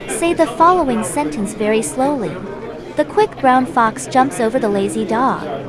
rhythm0_Babble_1.wav